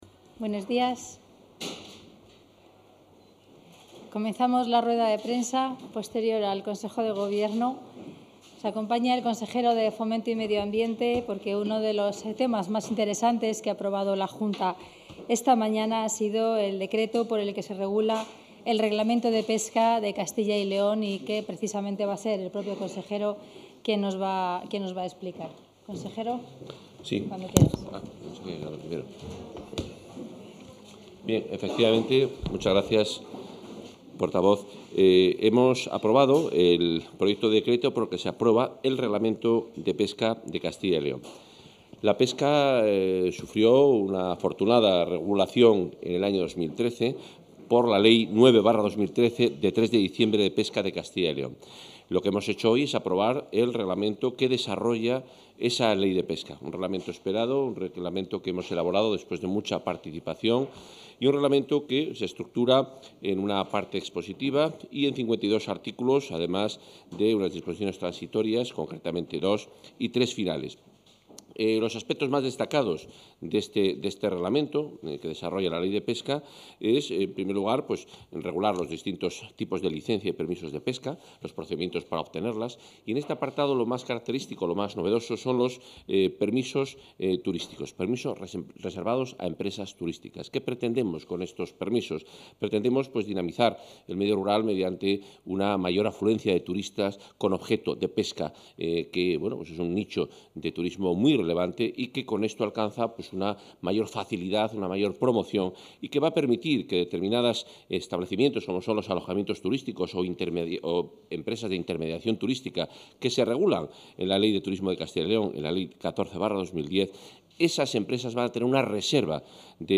Rueda de prensa tras el Consejo de Gobierno.